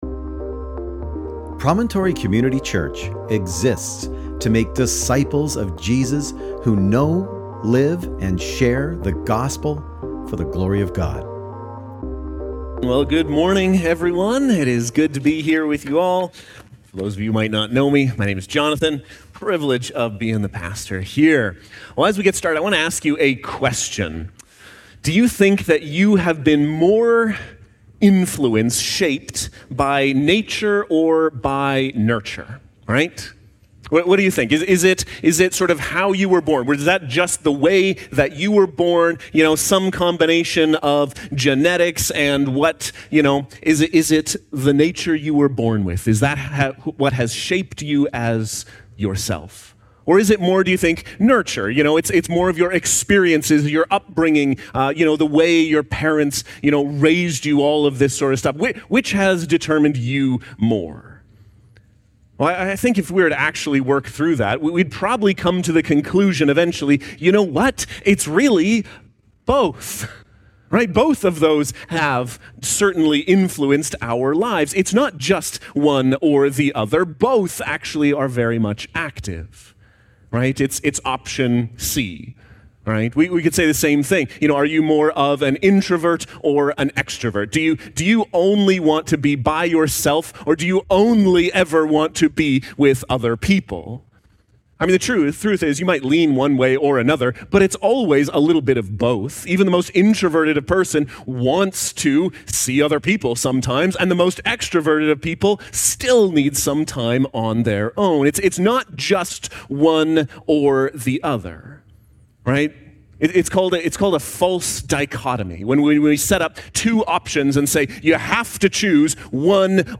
Sermon Text: Galatians 2:17-21